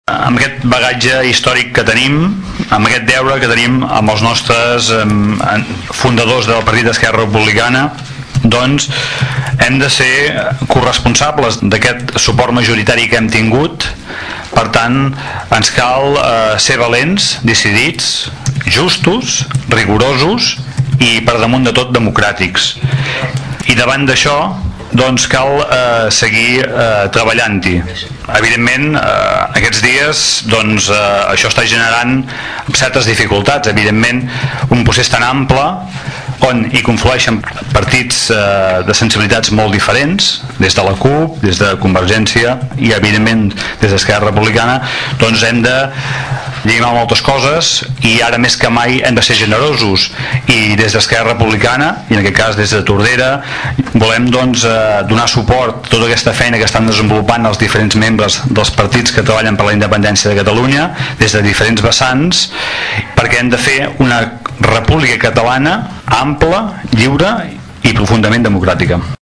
El regidor d’ERC, Xavier Pla, qualifica d’històrica aquesta moció, pels moments també històrics que s’estan vivint a Catalunya. Diu que tenen la responsabilitat de lluitar per una Catalunya més lliure i més justa, tal i com van fer els regidors republicans del 1930.